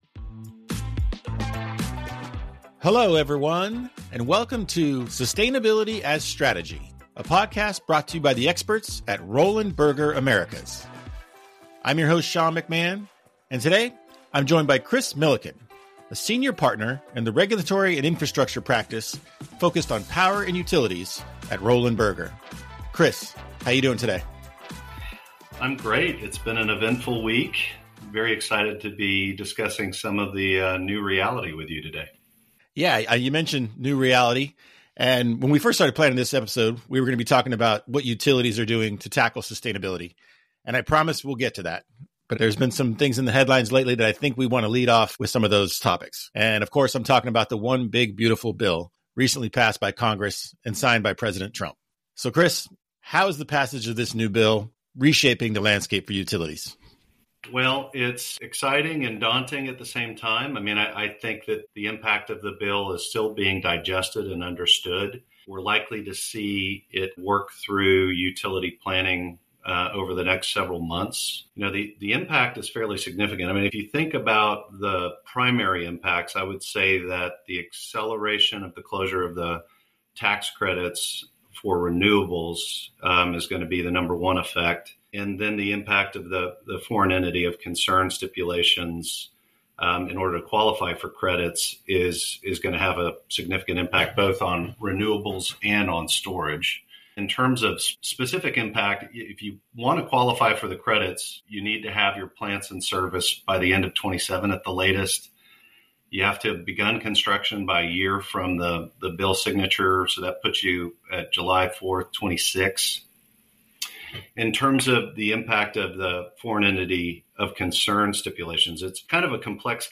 Each episode features candid conversations with our industry leaders from the Americas who tackle sustainability head-on, deploy innovative solutions, and drive real commercial impact.